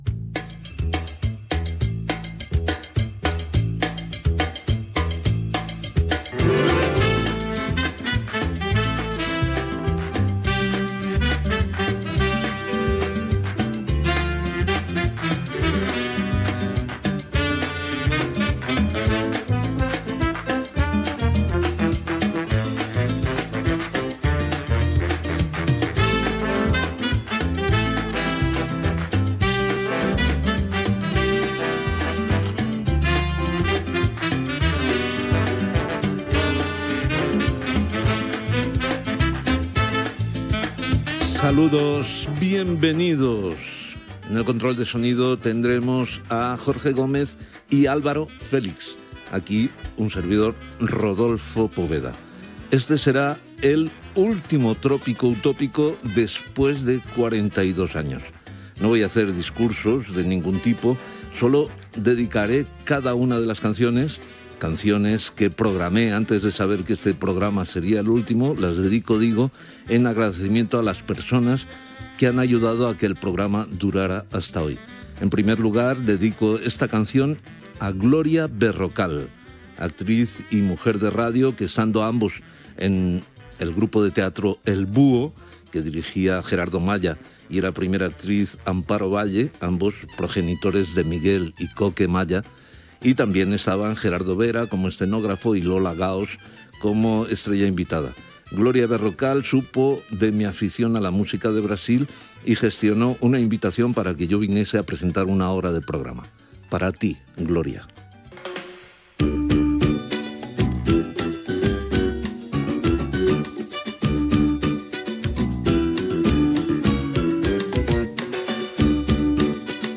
Fragments del darrer programa després de 42 anys en antena.
Música, equip, presentació amb esment a que comença l'últim programa.
Musical